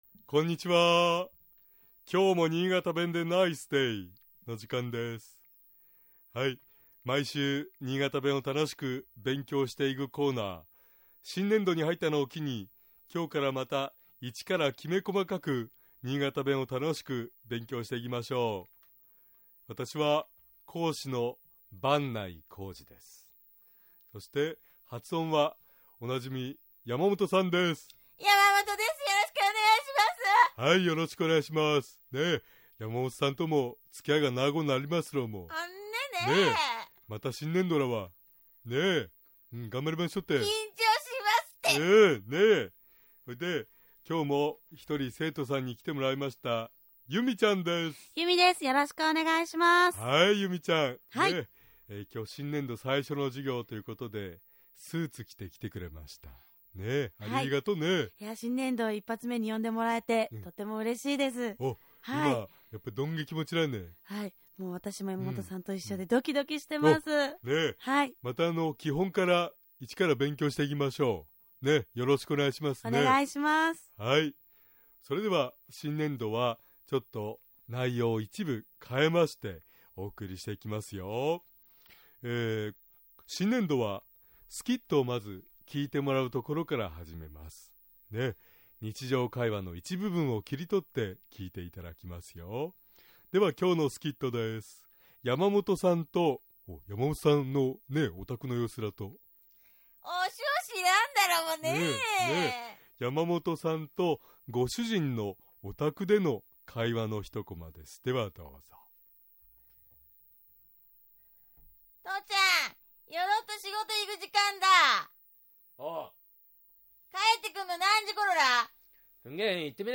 今回は断定の「ら」について勉強しましょう。 新潟弁では「～だ」と言う場合「～ら」と発音します。
尚、このコーナーで紹介している言葉は、 主に新潟市とその周辺で使われている方言ですが、 それでも、世代や地域によって、 使い方、解釈、発音、アクセントなどに 微妙な違いがある事を御了承下さい。